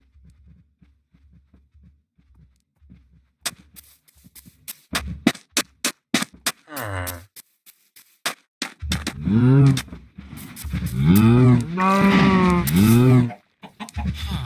When playing on a minecraft realm, constant sounds like walking on grass doesn't sound correct. It seems to cut out half way through playing the sound and repeats that again and again.
Other sounds like a sheep seem fine but consistant sounds e.g walking on grass, sand, etc cut out half way through and sound horrible.